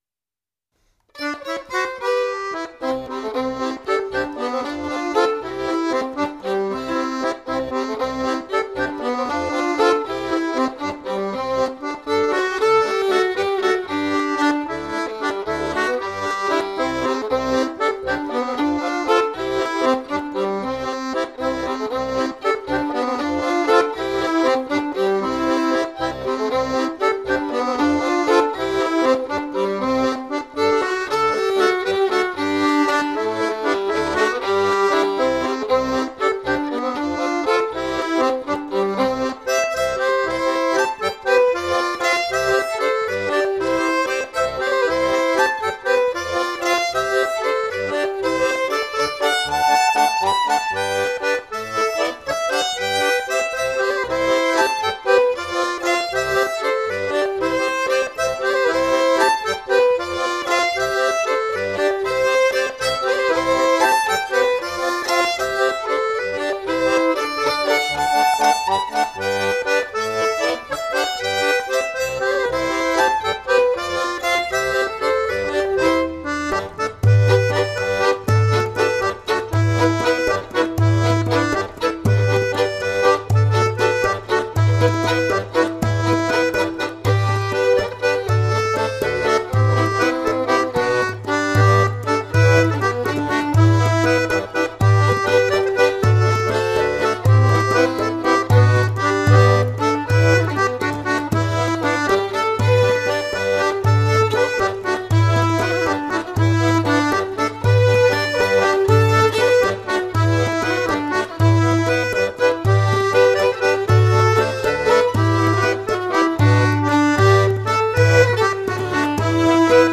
deux valses à 5 temps un peu impaires 2'34